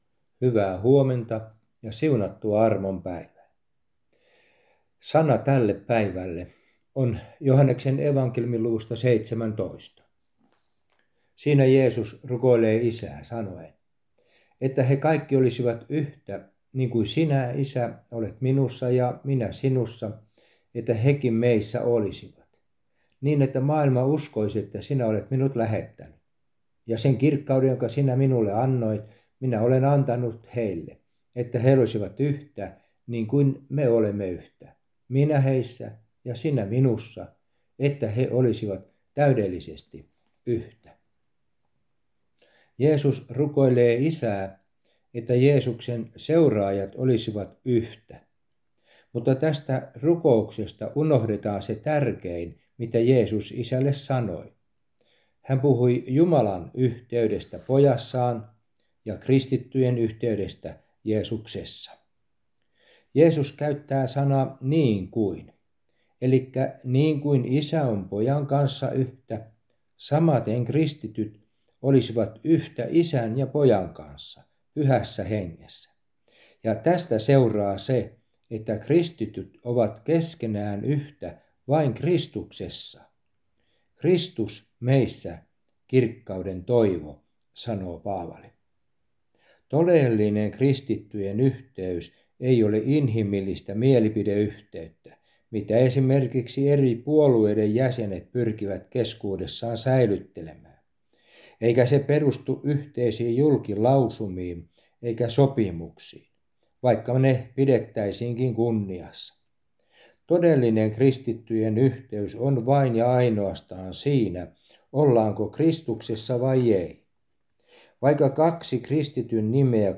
Aamuhartaus Järviradioon 15.11.2023